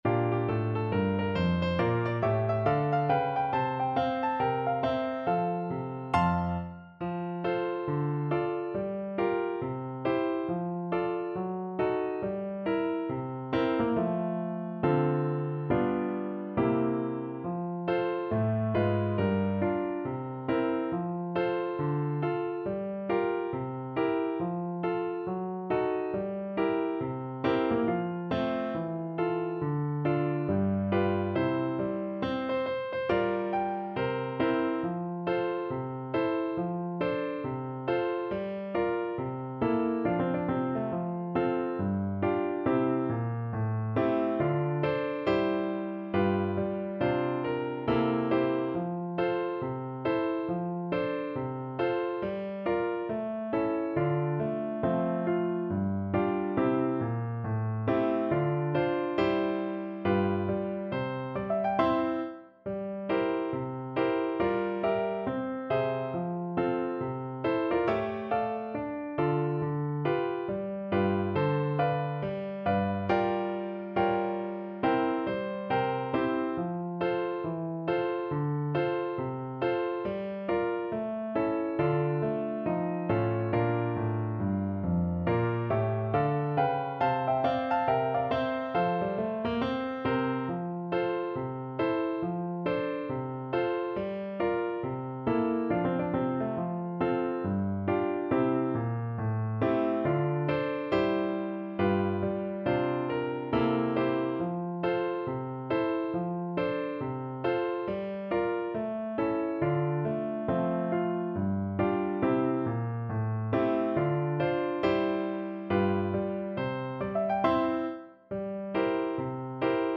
With a swing =c.69
4/4 (View more 4/4 Music)
Pop (View more Pop French Horn Music)